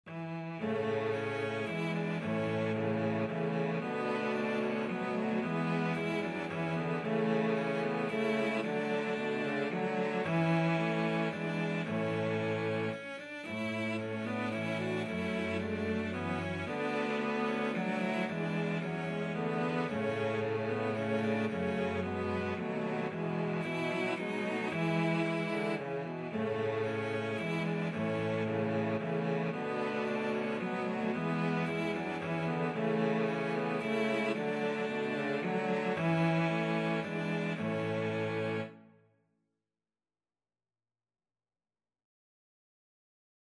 Cello 1Cello 2Cello 3Cello 4
Moderately Fast ( = c. 112)
3/4 (View more 3/4 Music)
Cello Quartet  (View more Easy Cello Quartet Music)